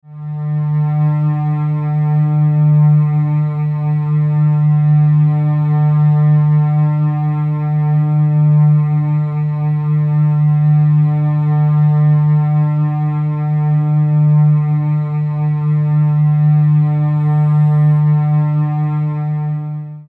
Music tones for String Animation Below
08_Low_D.mp3